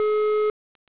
SubscriberBusy_CEPT.wav